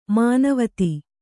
♪ mānavati